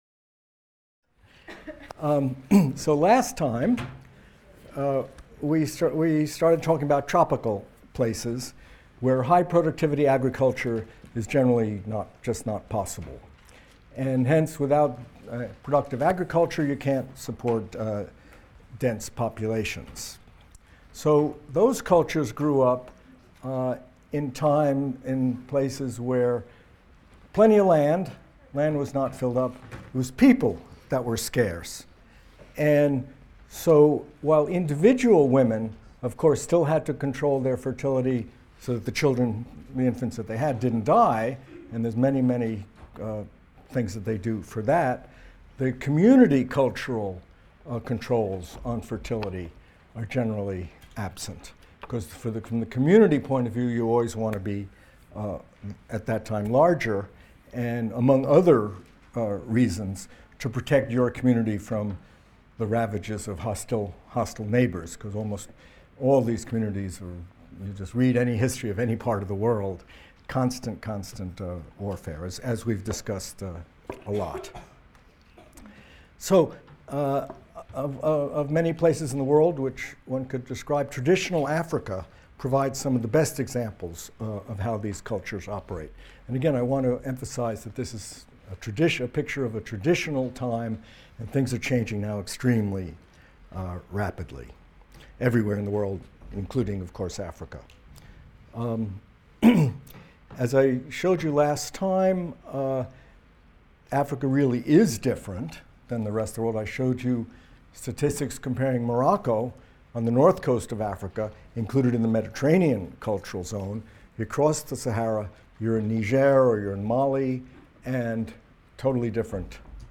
MCDB 150 - Lecture 6 - Malthusian Times | Open Yale Courses